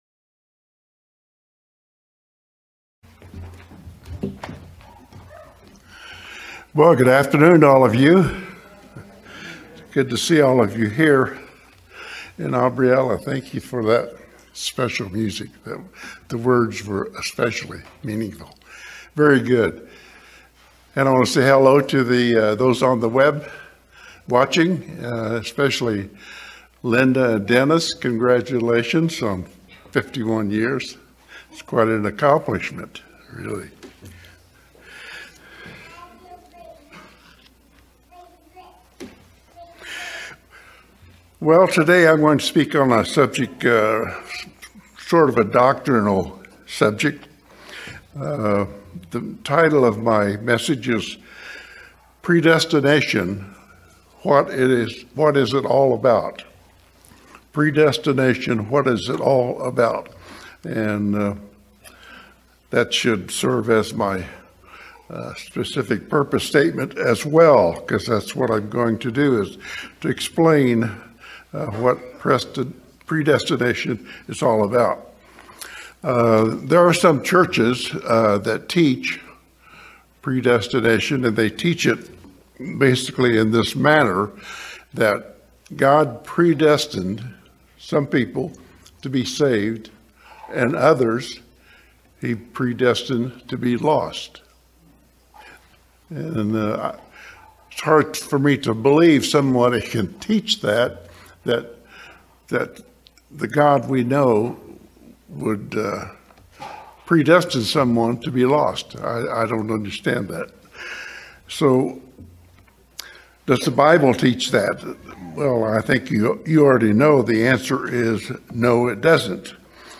Sermons
Given in Las Vegas, NV Redlands, CA San Diego, CA